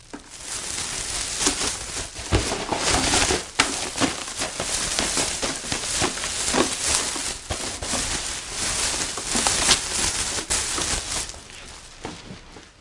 窸窸窣窣的空垃圾袋
描述：一个空垃圾袋的沙沙声。
Tag: 萧萧 垃圾袋